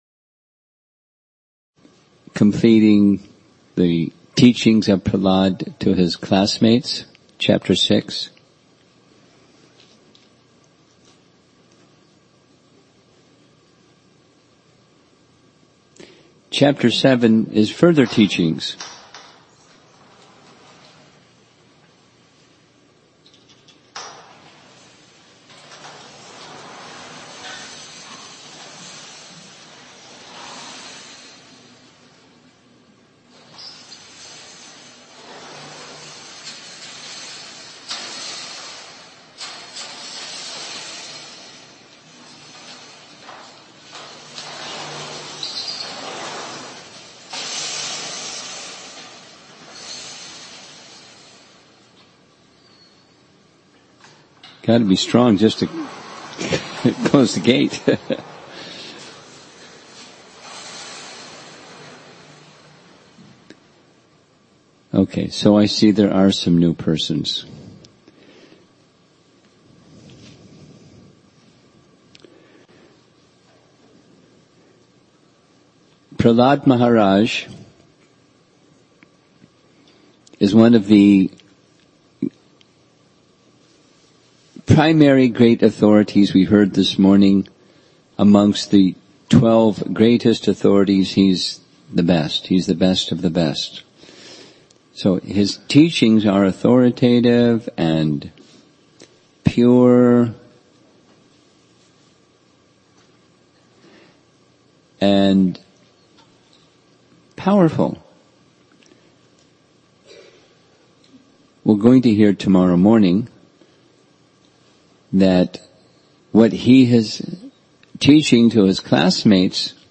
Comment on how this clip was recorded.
Session 7 Thanksgiving Day Retreat Central New Jersey November 2018